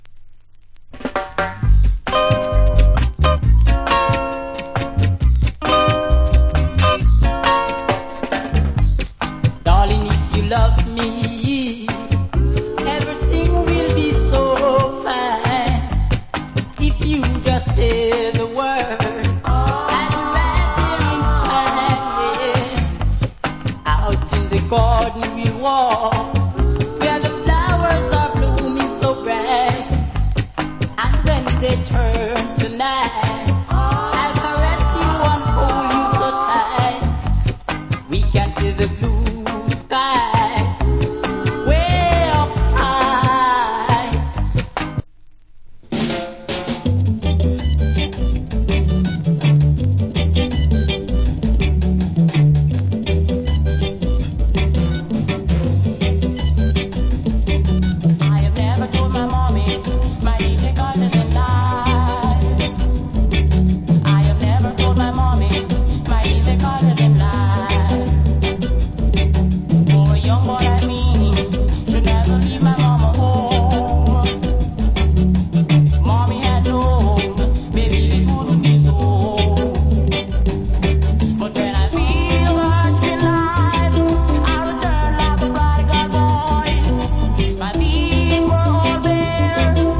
Wicked Early Reggae.